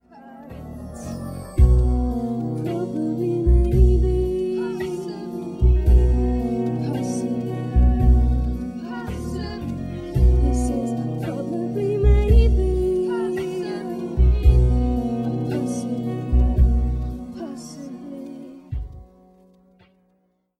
Tónica Si